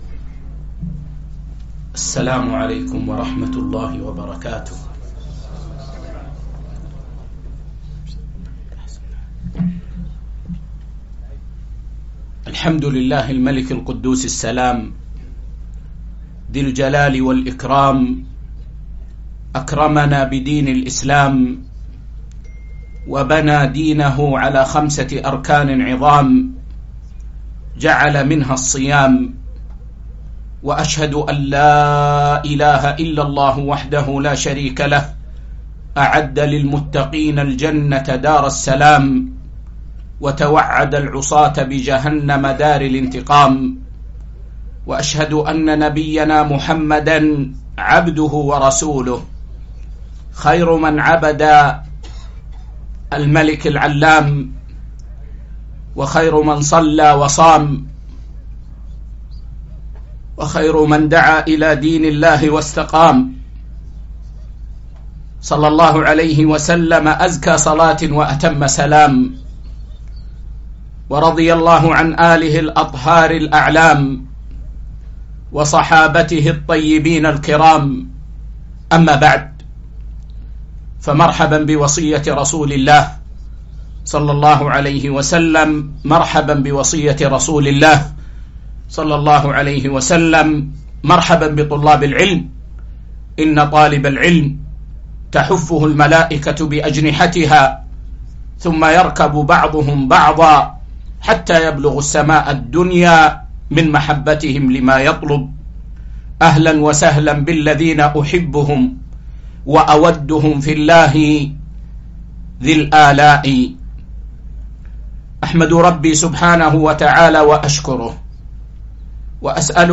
محاضرة - يا باغي الخير أقبل ٢٨ شعبان ١٤٤٥ هـ